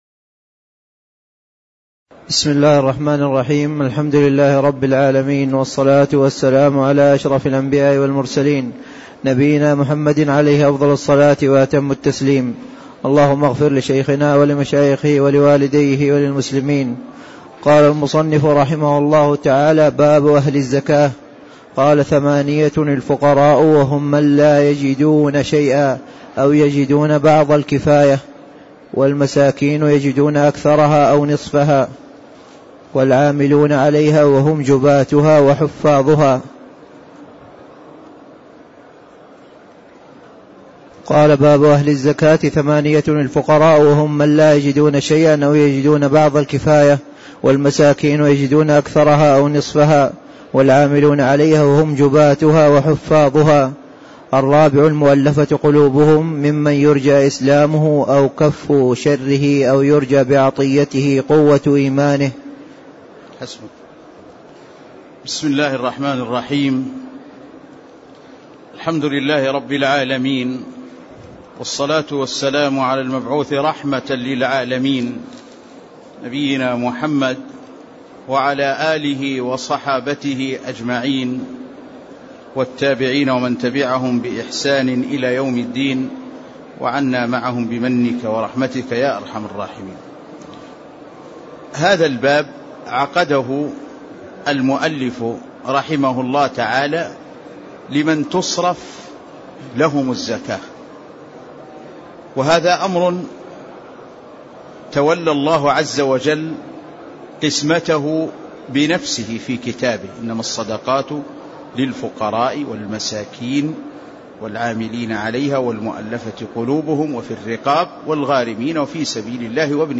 تاريخ النشر ٢٦ ربيع الثاني ١٤٣٦ هـ المكان: المسجد النبوي الشيخ